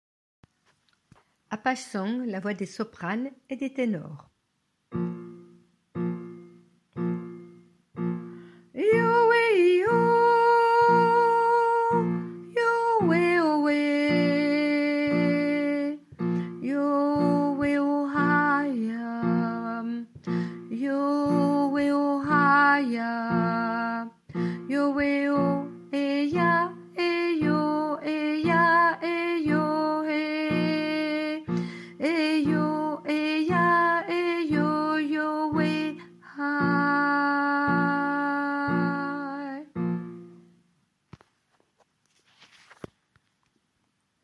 Apache song soprano et ténor